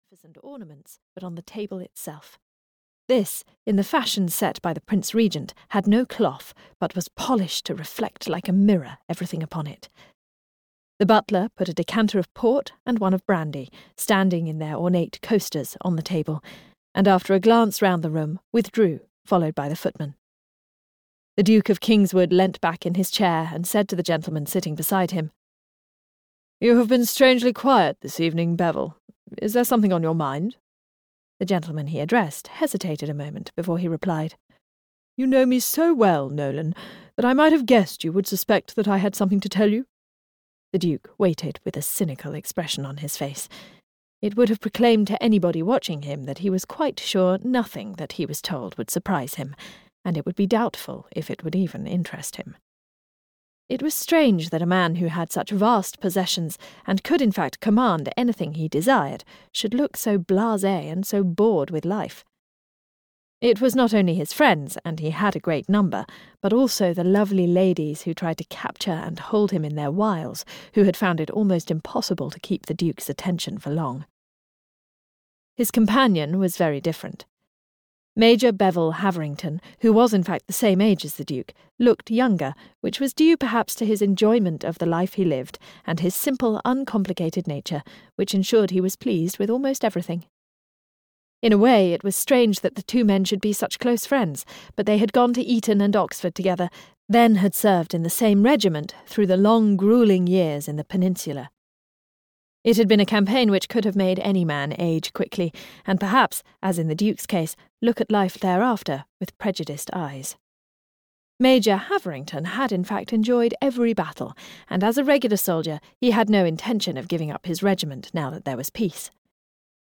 Audio knihaThe Duke and the Preacher's Daughter (EN)
Ukázka z knihy